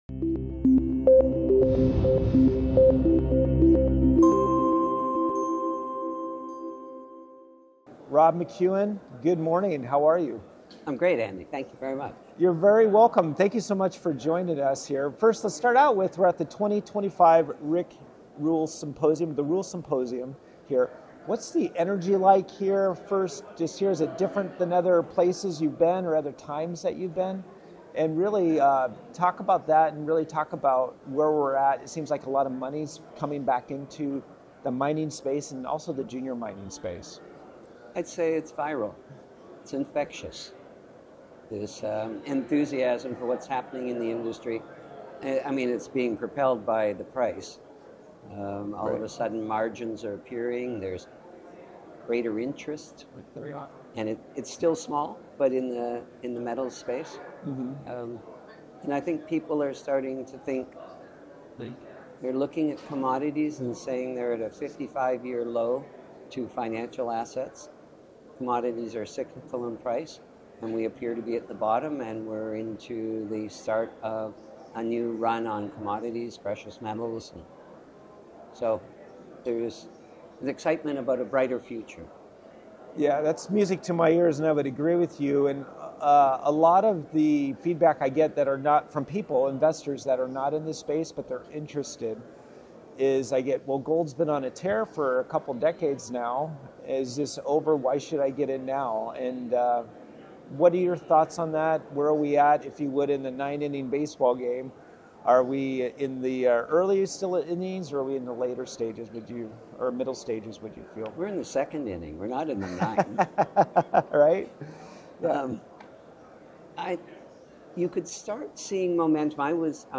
In this engaging episode at the 2025 Rick Rule Symposium, renowned mining executive Rob McEwen discusses the vibrant energy and growing investor interest in